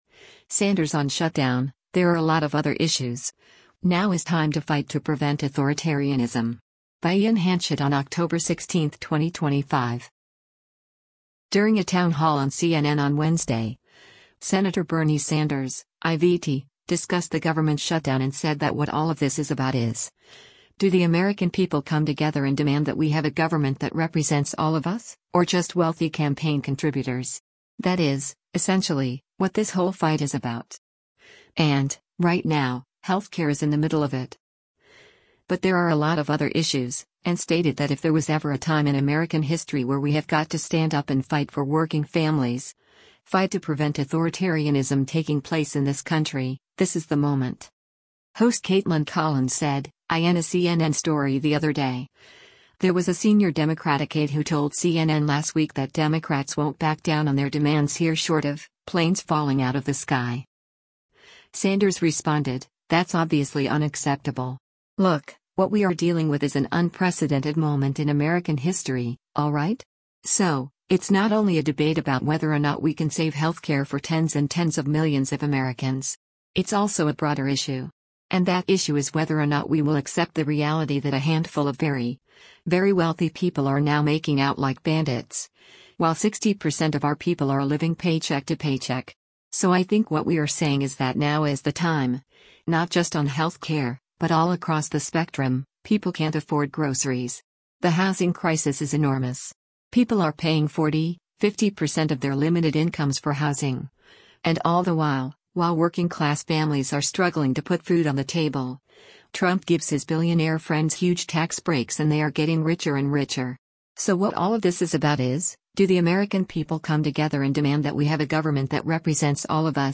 During a town hall on CNN on Wednesday, Sen. Bernie Sanders (I-VT) discussed the government shutdown and said that “what all of this is about is, do the American people come together and demand that we have a government that represents all of us, or just wealthy campaign contributors? That is, essentially, what this whole fight is about. And, right now, health care is in the middle of it. But there are a lot of other issues.” And stated that “if there was ever a time in American history where we have got to stand up and fight for working families, fight to prevent authoritarianism taking place in this country, this is the moment.”